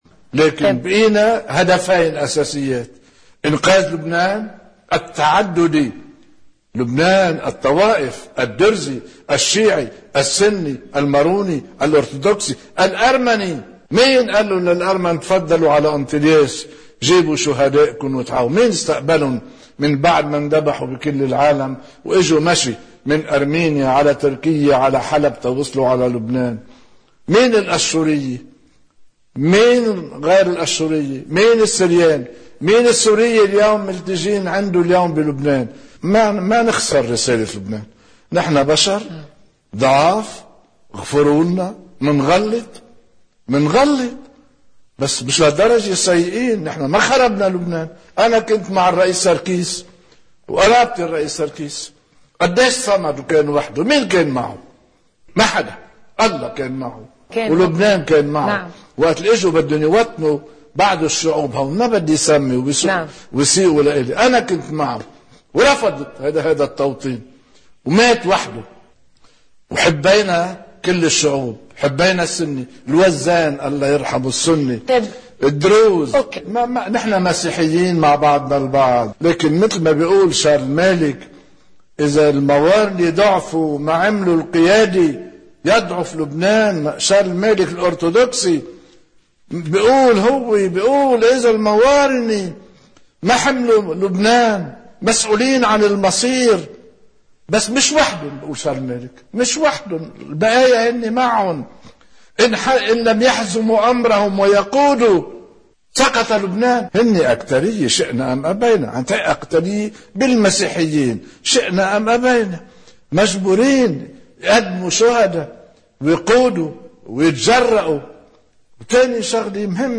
تعلّق الإعلامية في نورسات، والتعددية اليوم في أوروبا تواجه العلمنة الذي نحن نرفضها على الأكيد،